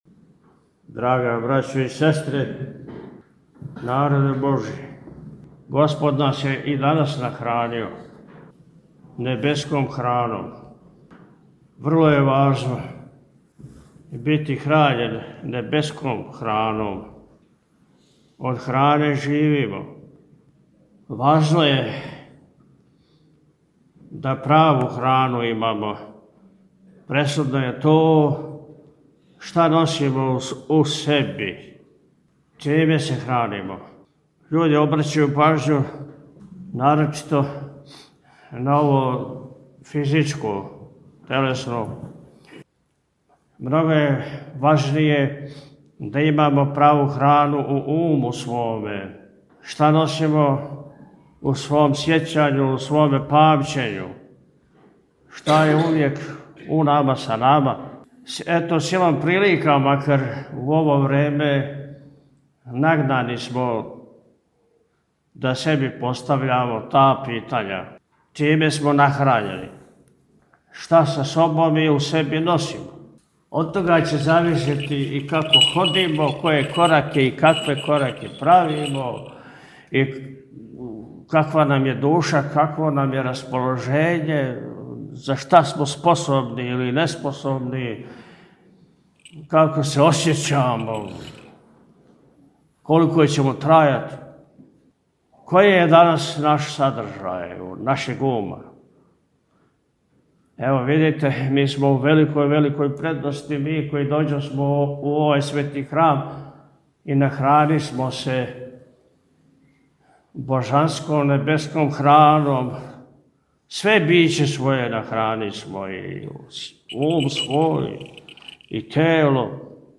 Друге недеље по Духовима, 22. јуна 2025. године, Његово Високопреосвештенство Архиепископ и Митрополит милешевски г. Атанасије служио је Свету архијерејску Литургију у храму Вазнесења Христовог [...]
Беседу Митрополита Атанасија можете послушати овде: